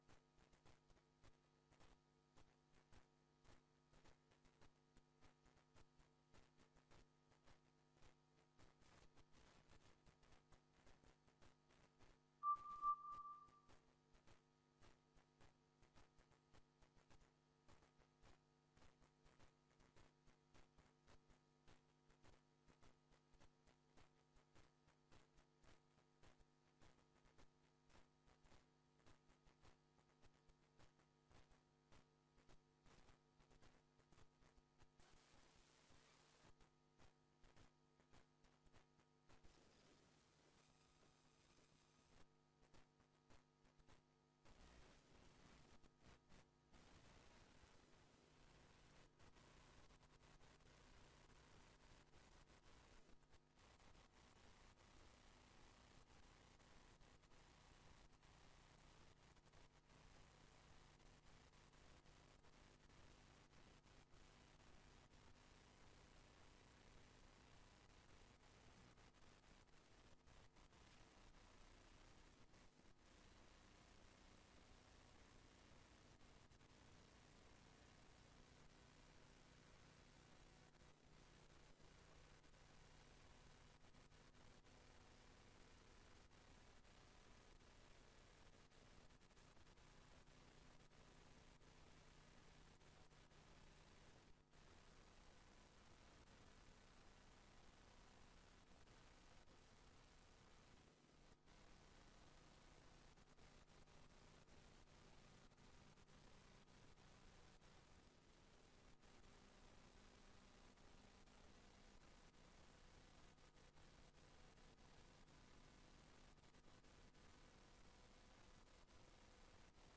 Raadsbijeenkomst 02 september 2025 19:30:00, Gemeente Tynaarlo
Korte presentatie / technische toelichting 'ontwerprichting renovatie gemeentehuis'
Locatie: Raadszaal